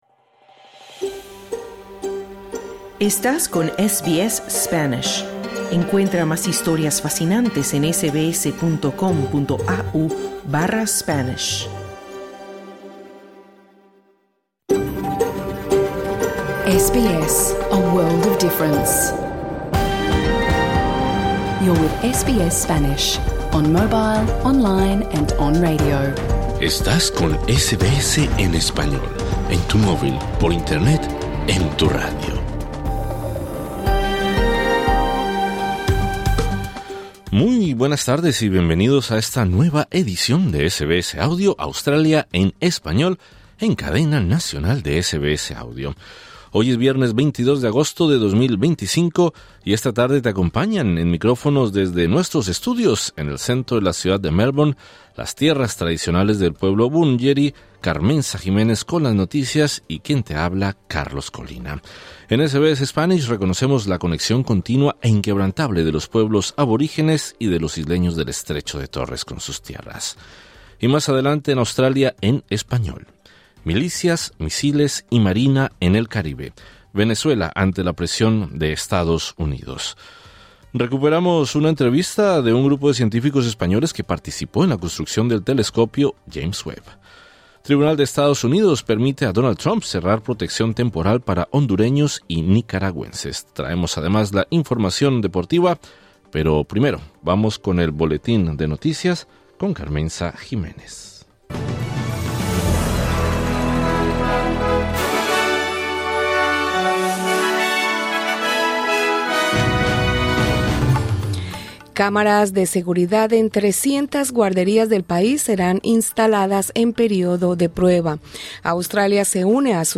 Hoy en el programa; milicias, misiles y marina en el Caribe: Venezuela resiste presión de EE. UU. Entrevista sobre el telescopio James Webb, fallo de Trump y TPS, y lo último en deporte.